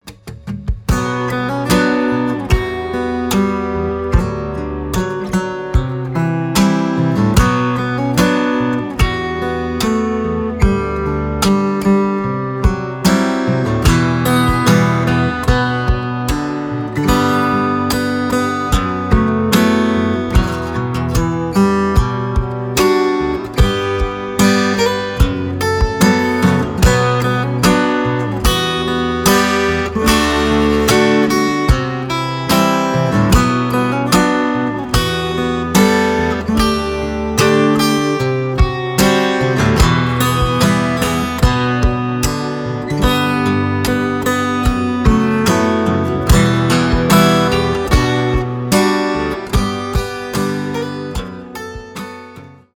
cover
без слов , инструментальные , гитара , акустика , рок